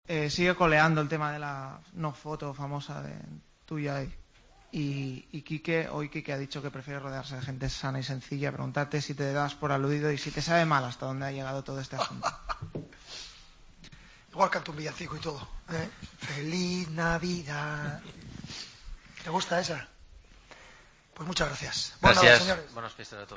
Luis Enrique termina la última rueda de prensa del año cantando Feliz Navidad
Preguntado por Quique Sánchez Flores, el técnico del Barcelona sorprendió a todos en la sala de prensa tras la goleada ante el Hércules.